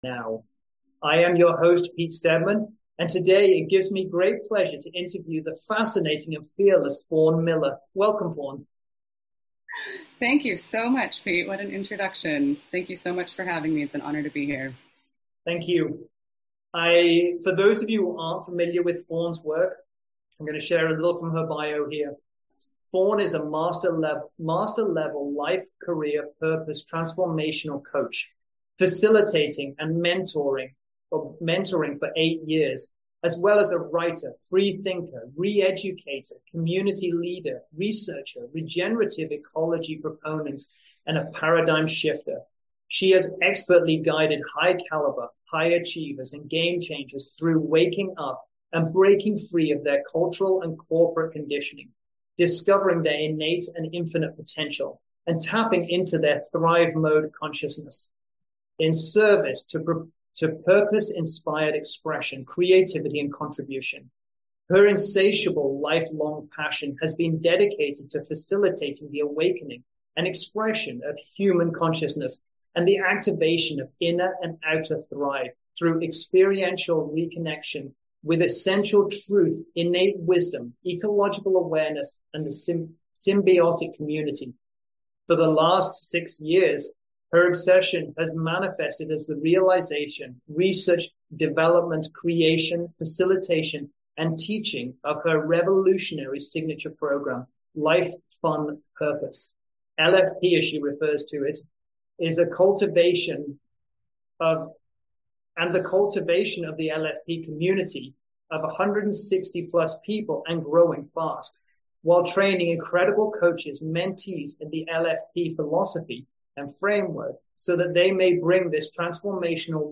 Interview for The Ripple Revolution